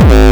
hardfloor kick.wav